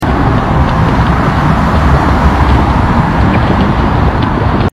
Normal LA Traffic Sound Effects Free Download